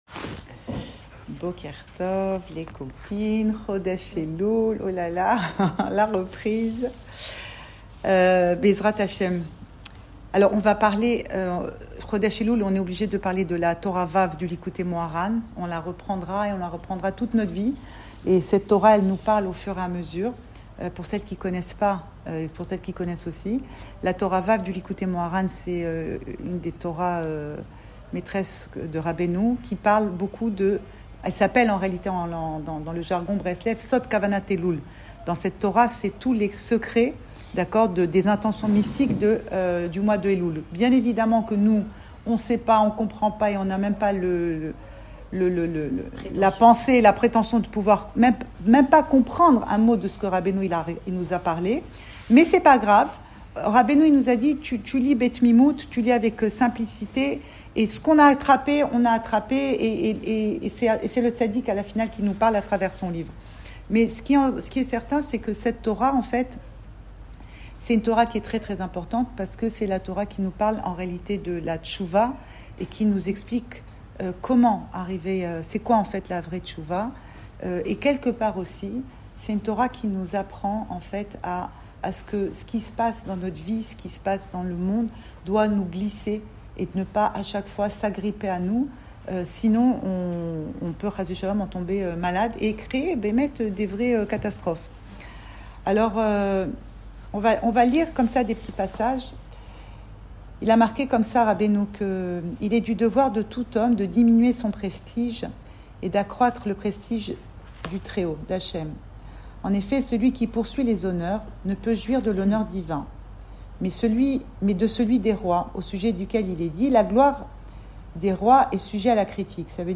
Cours audio Emouna Le coin des femmes Le fil de l'info Pensée Breslev - 27 août 2025 27 août 2025 Eloul… Enregistré à Tel Aviv